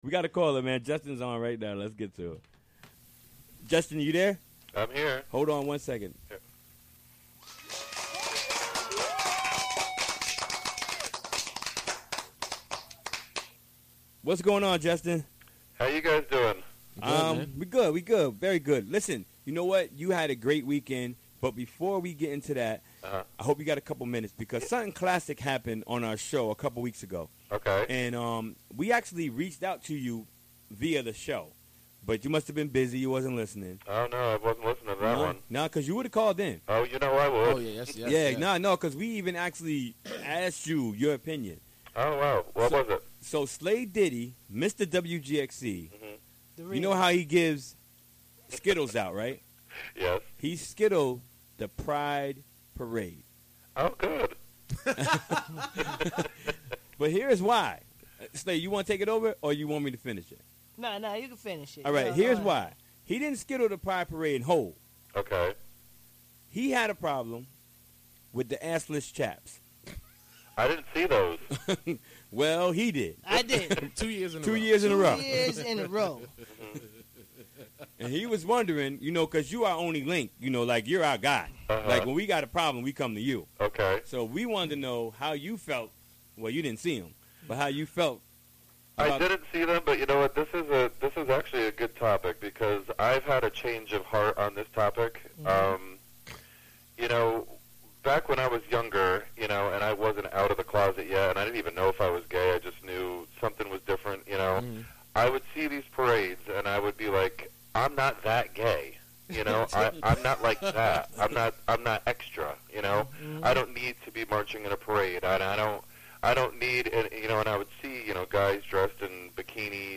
Recorded during the WGXC Afternoon Show on Wednesday, July 6, 2016.